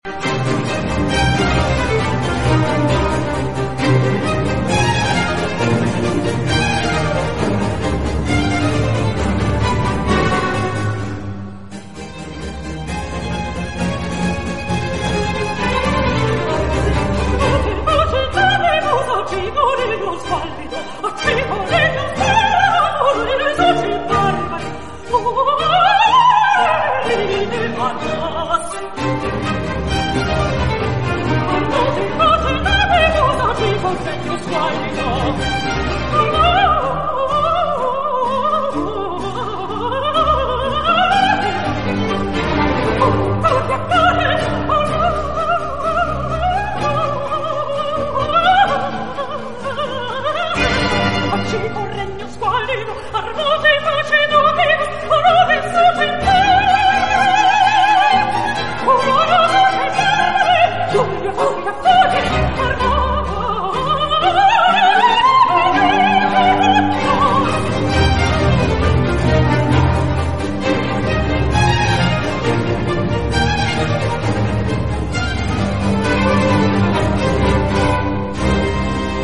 Emoke Barath singing Armatae face